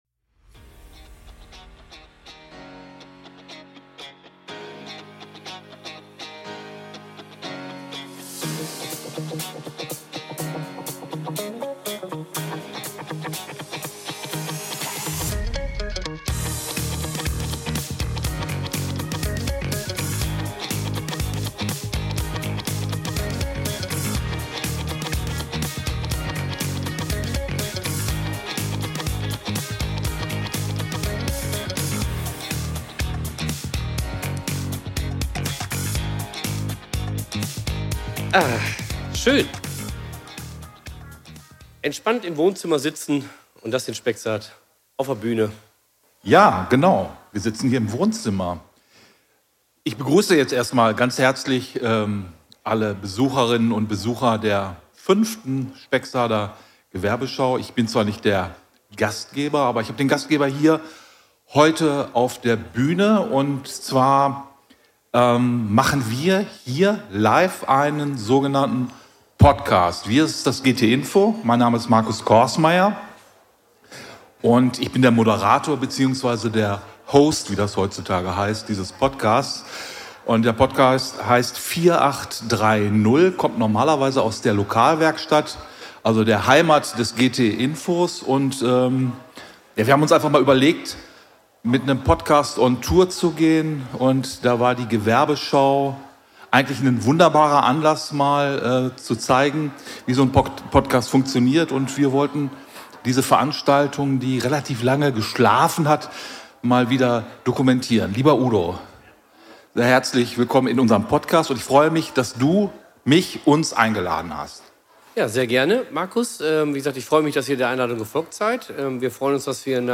Unterwegs und live auf der Spexarder Gewerbeschau ~ 4830 Podcast